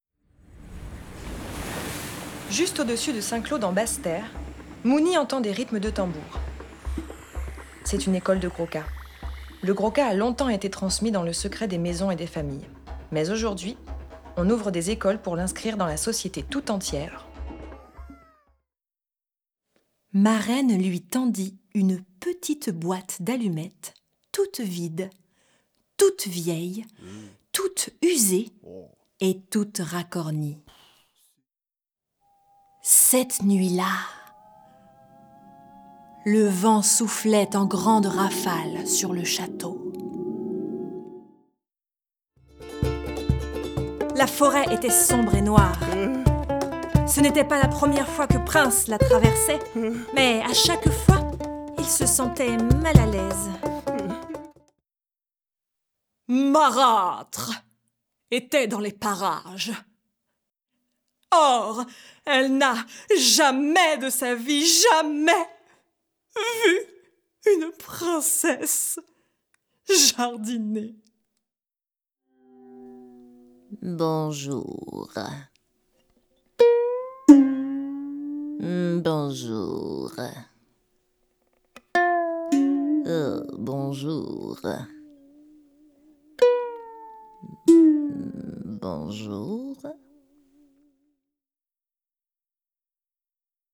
Bande démo voix off narration et personnage
- Mezzo-soprano